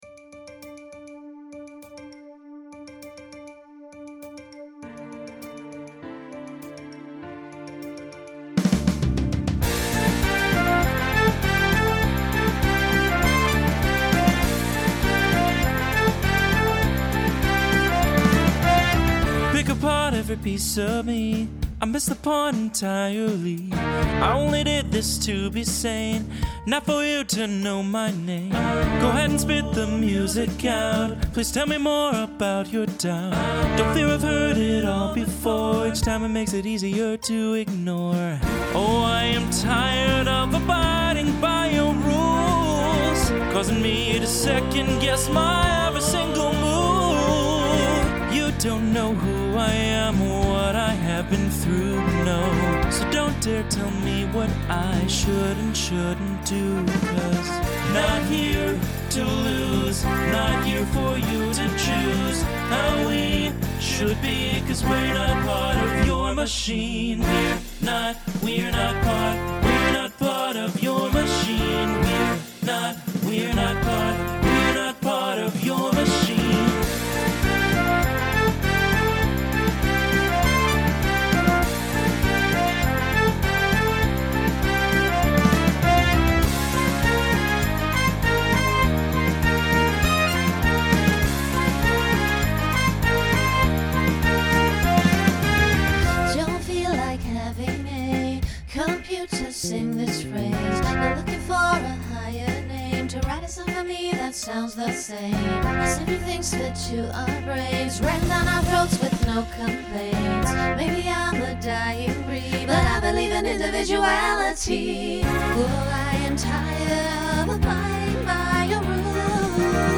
Starts TTB, then SSA, then ends SATB.
Genre Rock
Transition Voicing Mixed